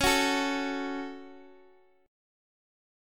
Listen to C#sus4#5 strummed